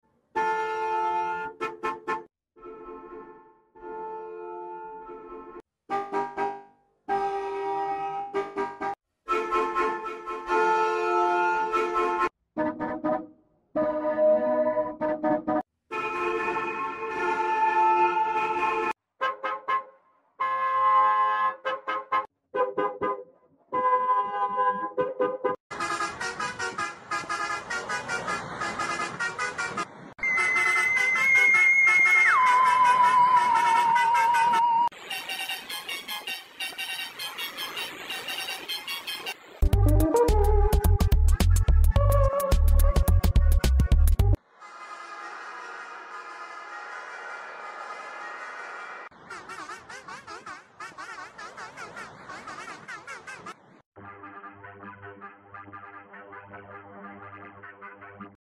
15 I bus horn VS sound effects free download
15 I bus horn VS Marcopolo bus horn Sound Variations In 57 Seconds